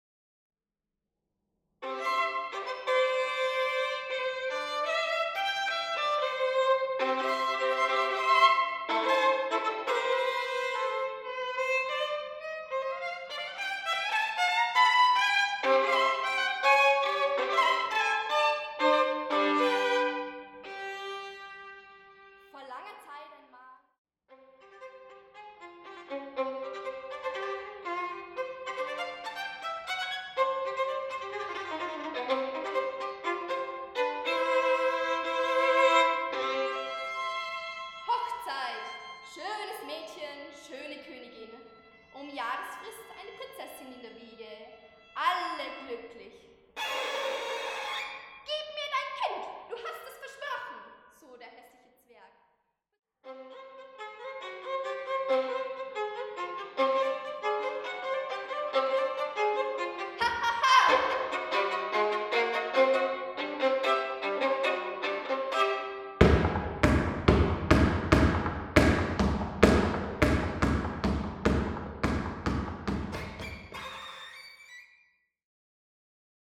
Neue Musik
Sololiteratur
Violine (1, mit Sprechstellen)